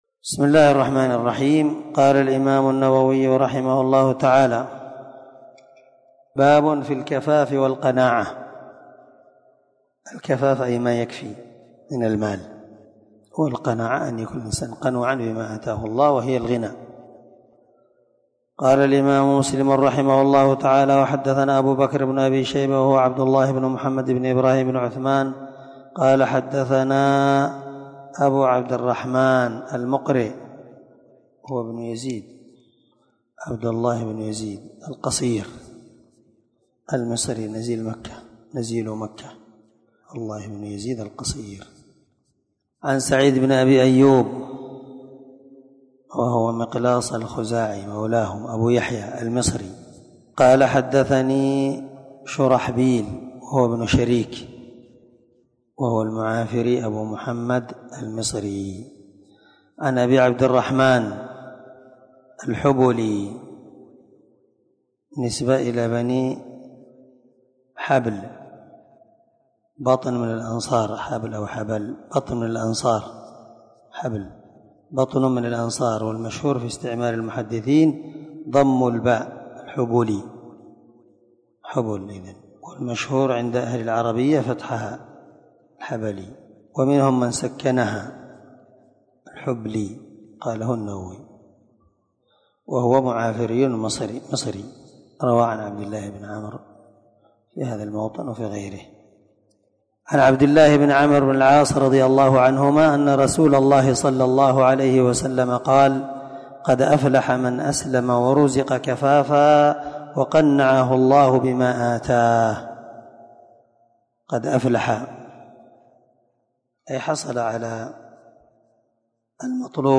650الدرس 58من شرح كتاب الزكاة حديث رقم(1054_1055) من صحيح مسلم
دار الحديث- المَحاوِلة- الصبيحة.